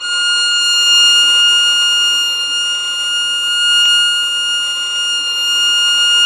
Index of /90_sSampleCDs/Roland L-CD702/VOL-1/STR_Violin 4 nv/STR_Vln4 % marc
STR VLN BO0N.wav